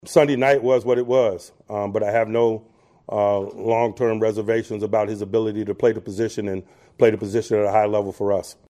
At his weekly media appearance yesterday, Tomlin said he is still confident in Aaron Rodgers to quarterback the Steelers.